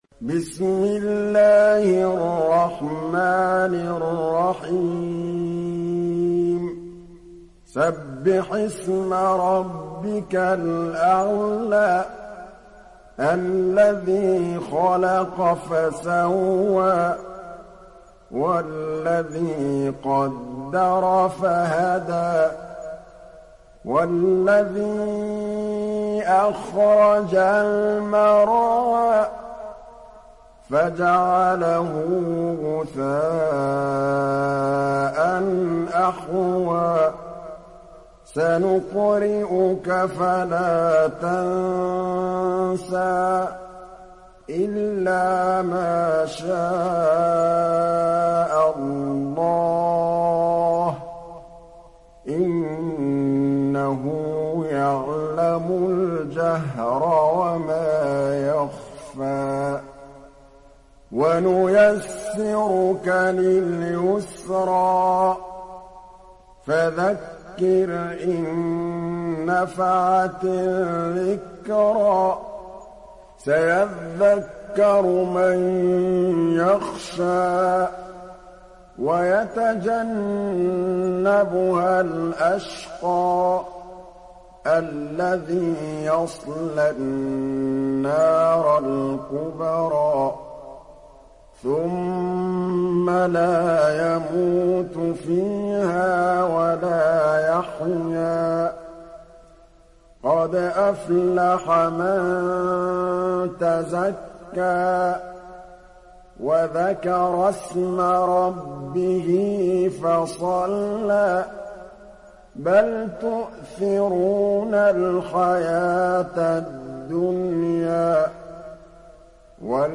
Ala Suresi mp3 İndir Muhammad Mahmood Al Tablawi (Riwayat Hafs)